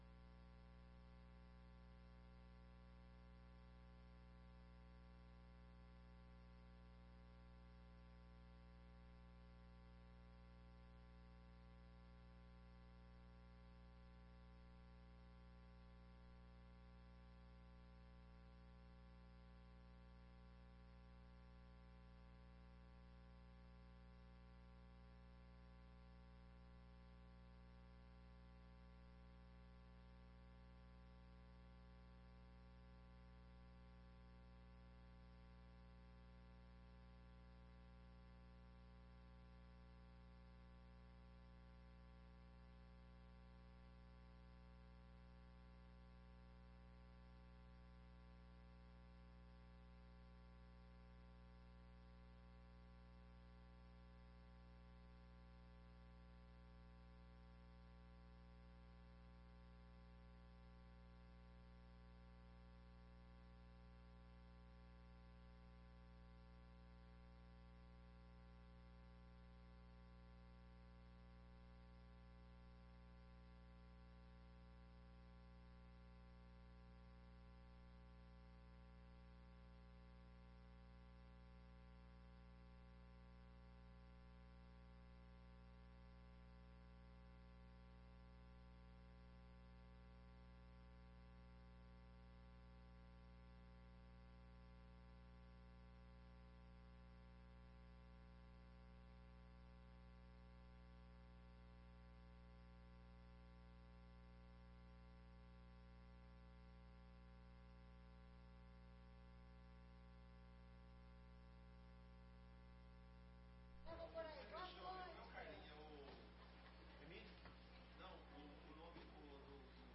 44ª Sessão Ordinária de 2017